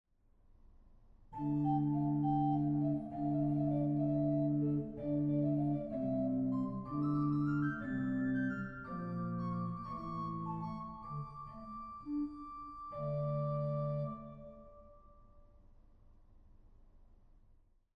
The church room has a medium-wet acoustics with about 3,5 seconds of reverberation.
All stops were recorded with multiple release levels for short, medium and long key attacks.
Organ temperament: Slightly modified equal temperament at a1=440 Hz.
Quintflöte 5 1/3
I-Quintfloete5.1.3.mp3